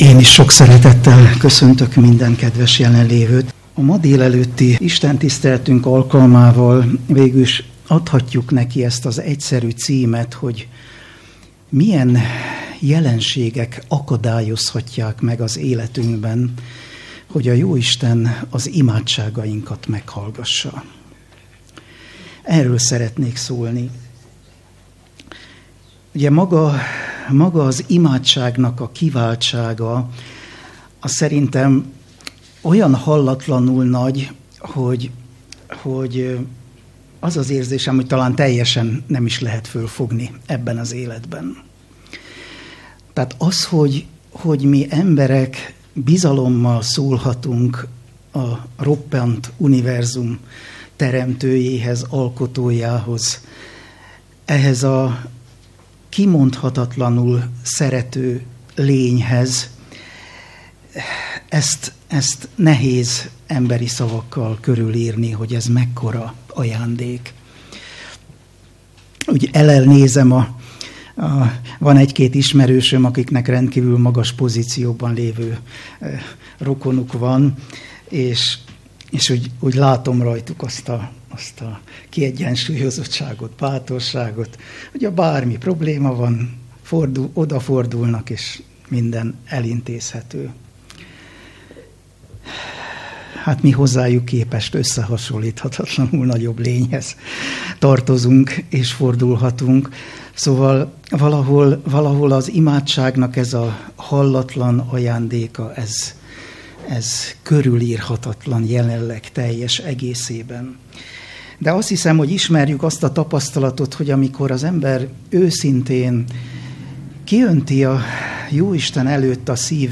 Szombati igehirdetés | Úrvacsora